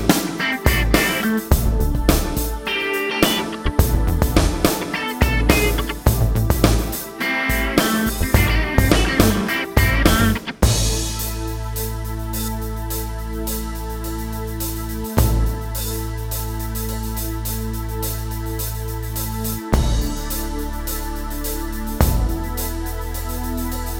No Main Guitar Rock 5:26 Buy £1.50